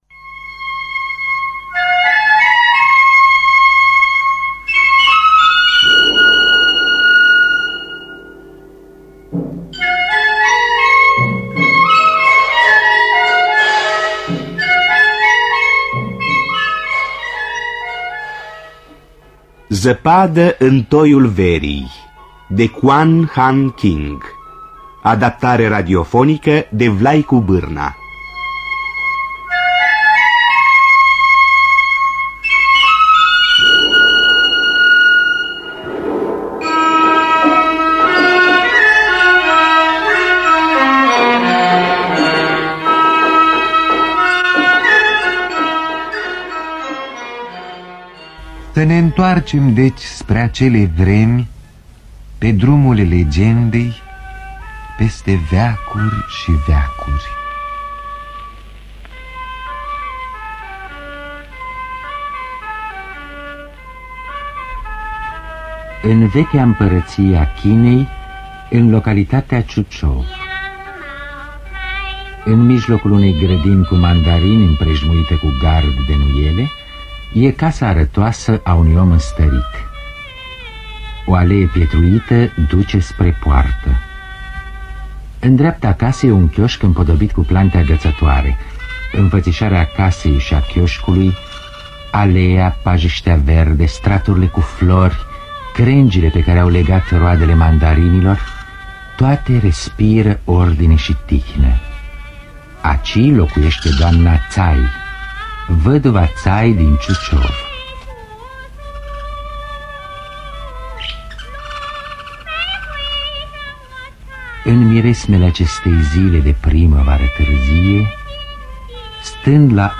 Traducerea și adaptarea radiofonică de Vlaicu Bârna.